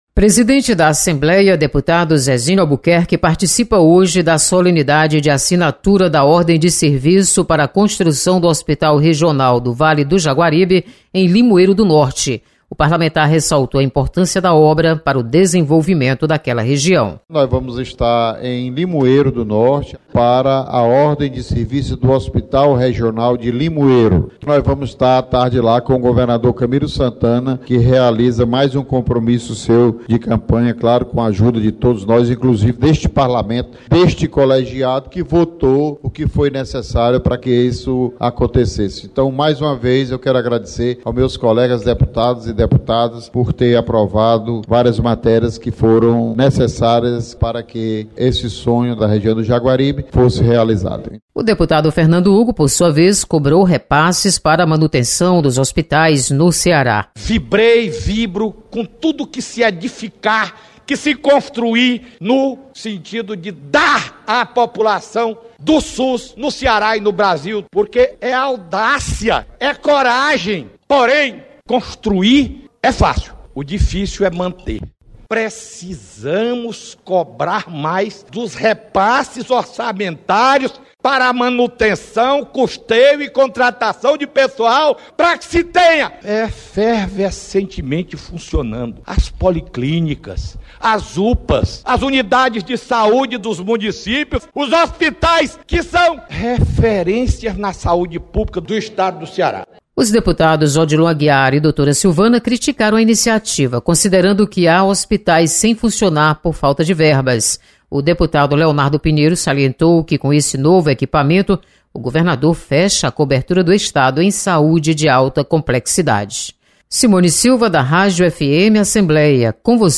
Zezinho Albuquerque anuncia ordem de serviço, em Limoeiro. Repórter